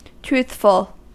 Ääntäminen
IPA : /ˈtruːθ.f(ə)l/